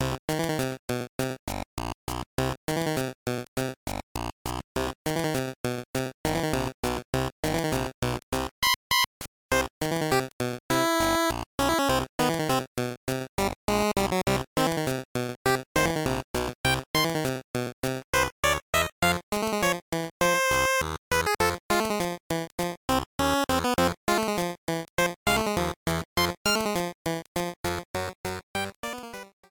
Trimmed to 30 seconds and applied fade-out when needed